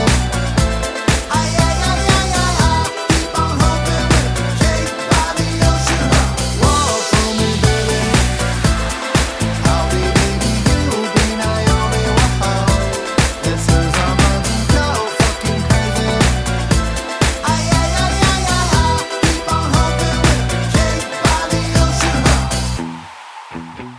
Clean Version Pop (2010s) 3:37 Buy £1.50